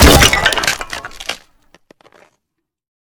DoorFigureBlast.ogg